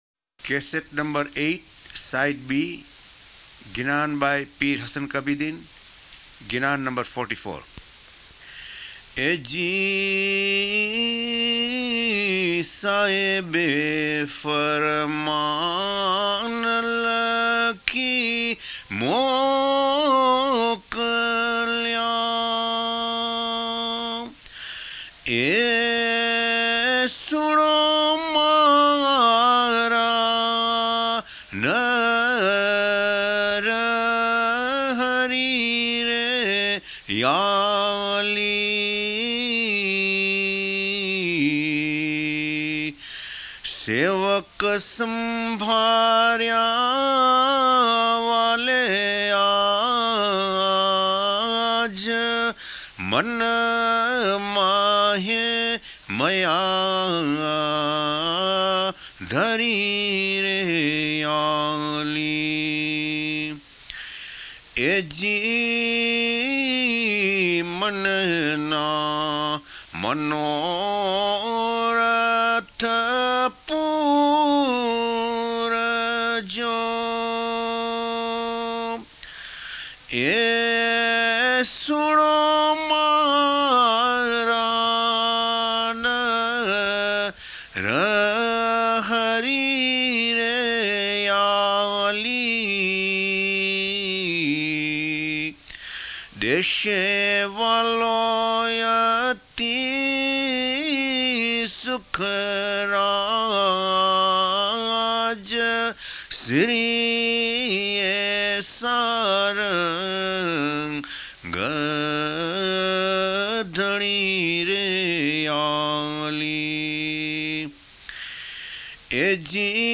Ginan Sahebe Farman Lakhi Mokalea by Pir Hasan Kabirdin; recitation